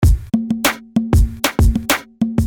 Bang This Drumz No Snare
标签： 96 bpm Rap Loops Drum Loops 430.71 KB wav Key : Unknown
声道立体声